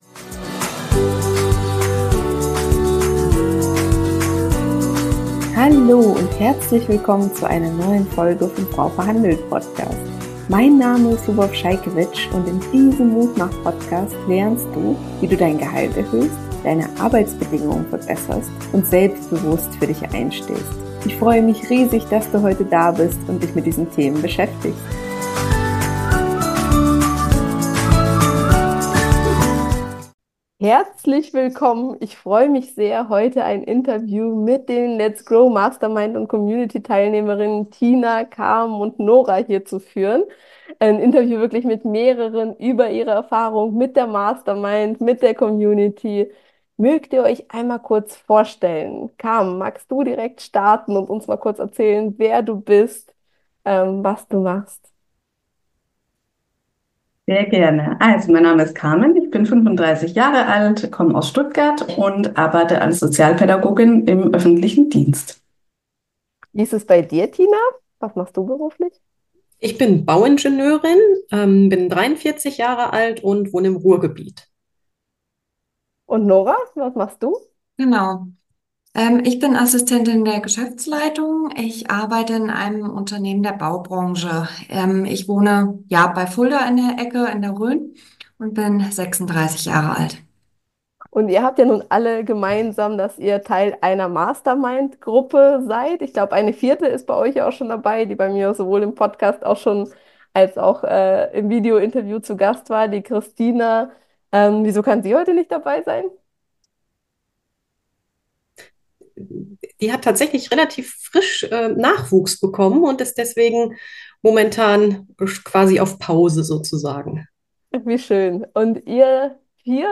drei Teilnehmerinnen der Let's Grow Mastermind und Community.